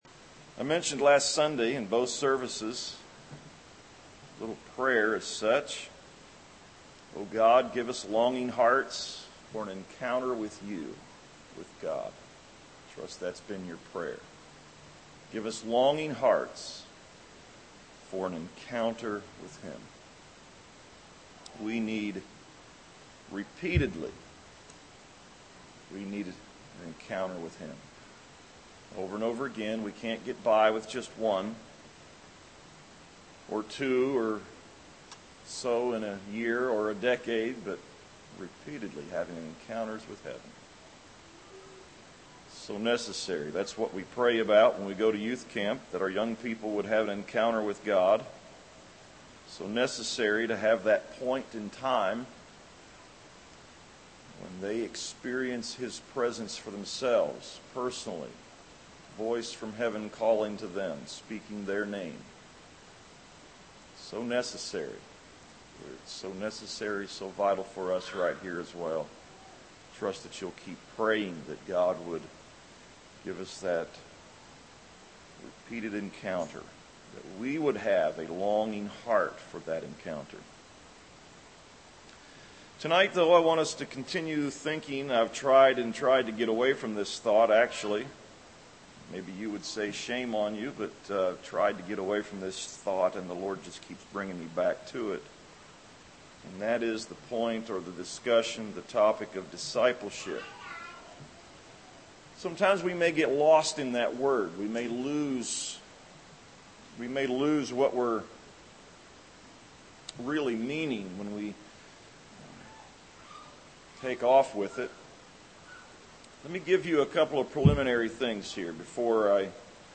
A sermon on Discipleship